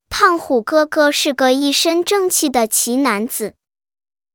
👉 TTS Online ▫ TTS Online是一款免费的文本转语音工具，提供语音合成服务，支持多种语言，包括英语、法语、德语、西班牙语、阿拉伯语、中文、日语、韩语等，以及多种语音风格。